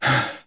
breath2.mp3